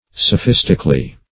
[1913 Webster] -- So*phis"tic*al*ly, adv. --